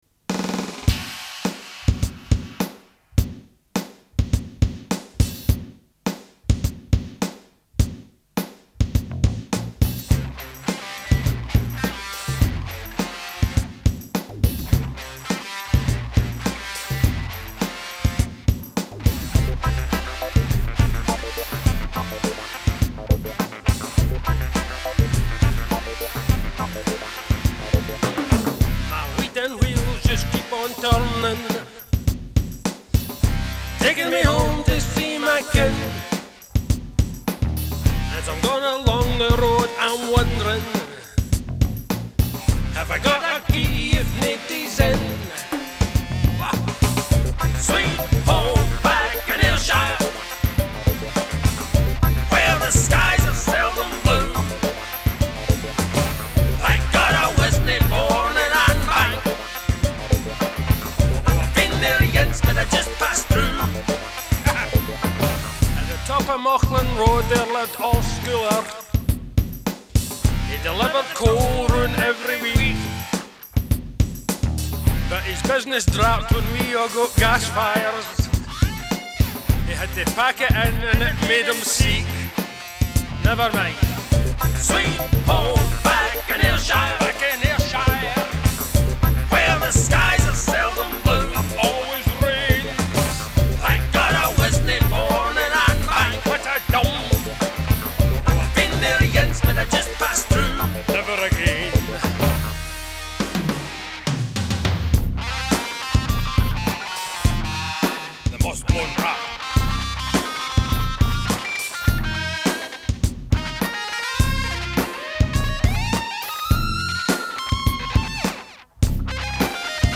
tongue in cheek cover version